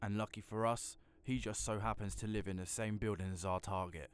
Added all voice lines in folders into the game folder